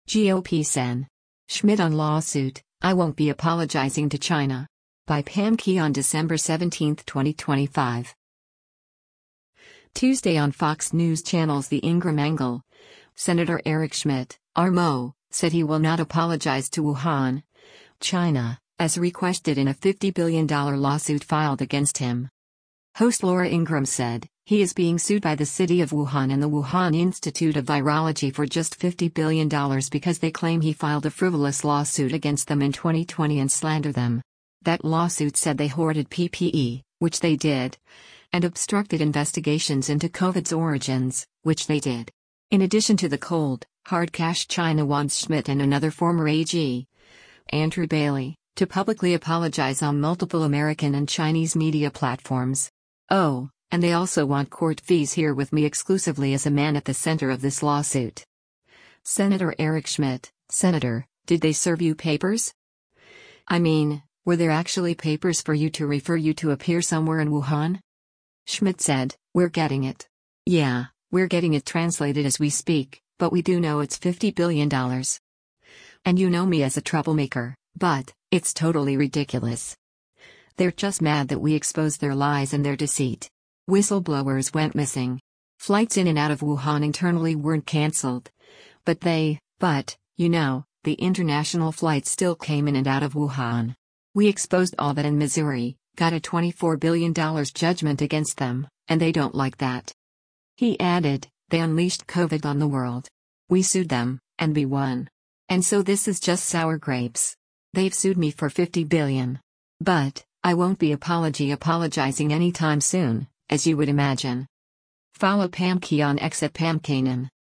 Tuesday on Fox News Channel’s “The Ingraham Angle,” Sen. Eric Schmitt (R-MO) said he will not apologize to Wuhan, China, as requested in a $50 billion lawsuit filed against him.